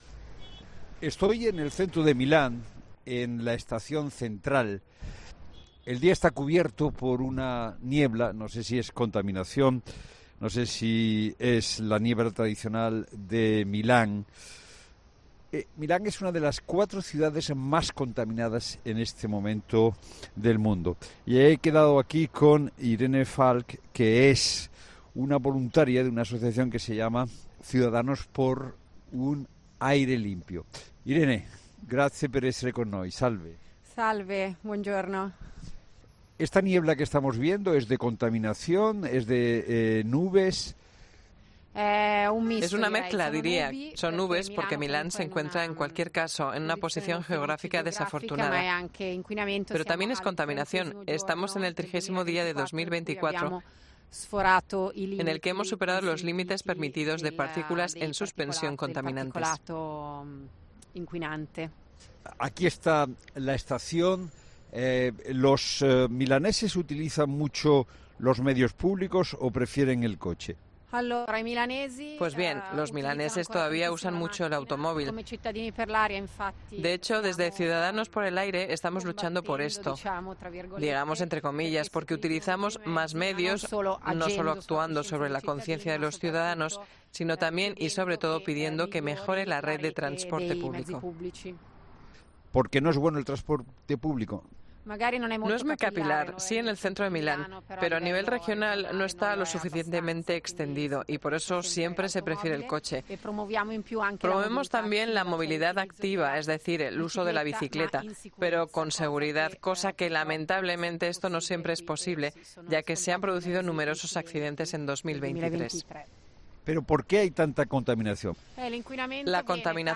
Milán, una de las cuatro ciudades más contaminadas del mundo